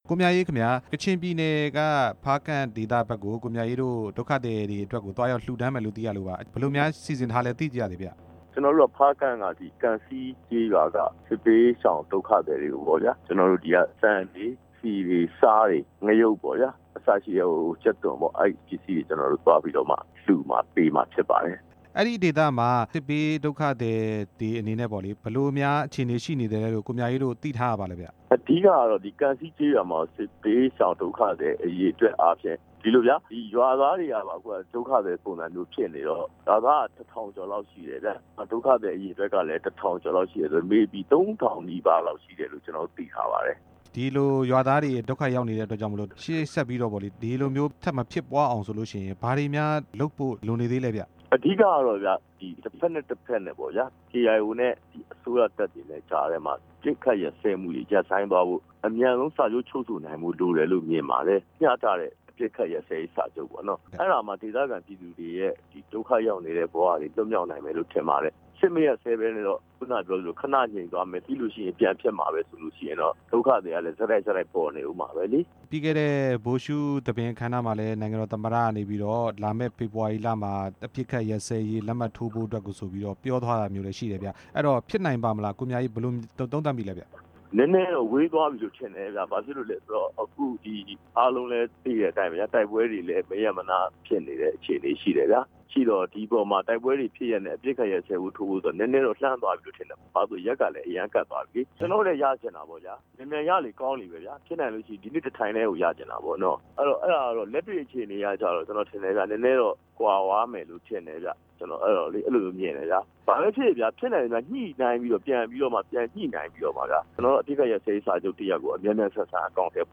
၈၈ မျိုးဆက်ငြိမ်းချမ်းရေးနဲ့ ပွင့်လင်းလူ့အဖွဲ့အစည်းက ကိုမြအေးနဲ့ မေးမြန်းချက်